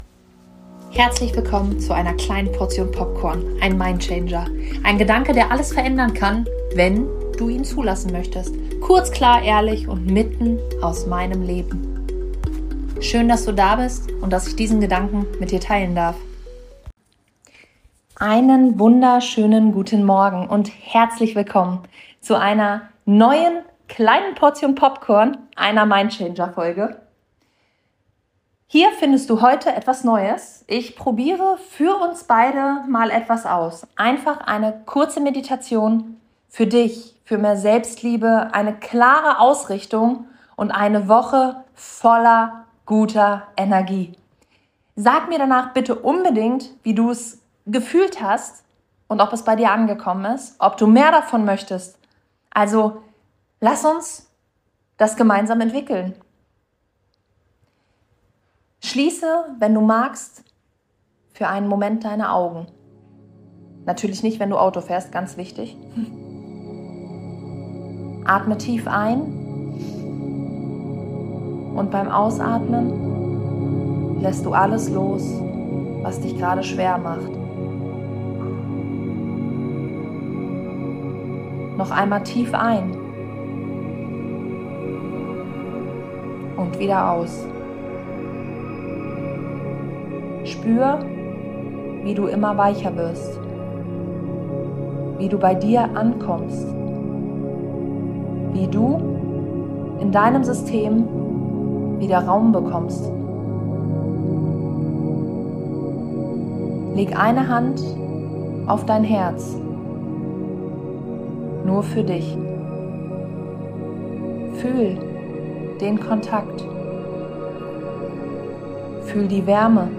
Meditation für deine Woche